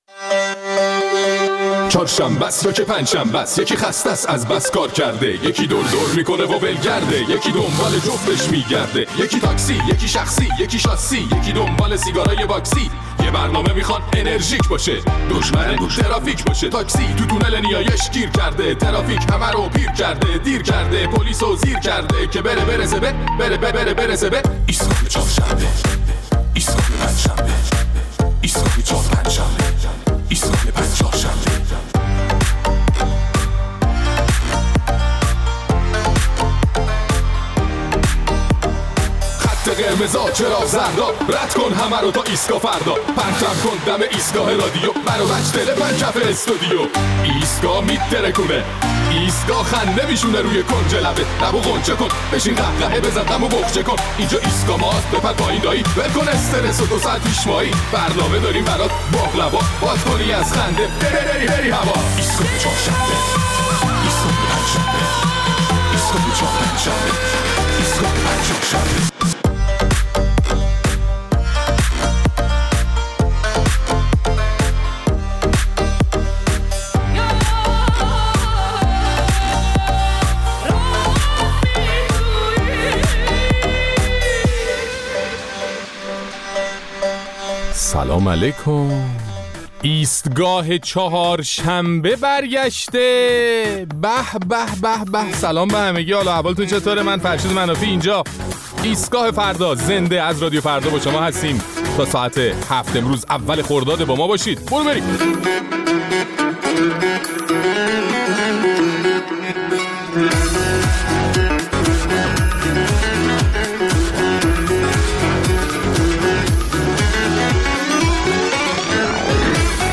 در این برنامه نظرات شنوندگان ایستگاه فردا را درباره صحبت‌های رئیس کل بانک مرکزی که گفته قله‌های مقاومت را طی کرده‌ایم پرسیده‌ایم.